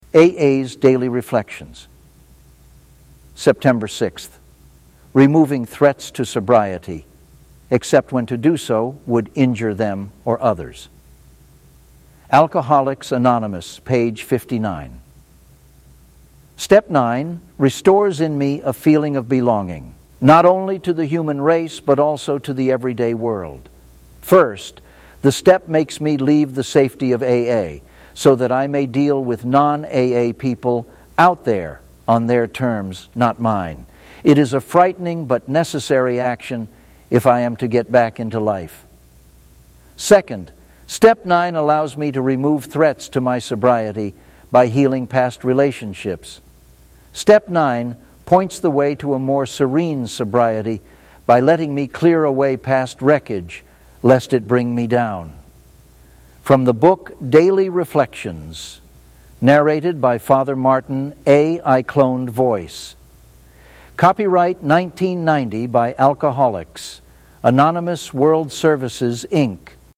A.I. Cloned Voice.